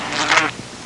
Dead Mosquito Sound Effect
Download a high-quality dead mosquito sound effect.
dead-mosquito.mp3